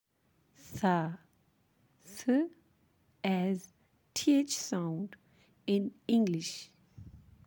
The letter tha ث equivalent in English is the combination of letters Th and it has “th” phonics.
How to pronounce tha ث
Letter tha ث is pronounce when the tip of tongue touch the lower end of the two top incisor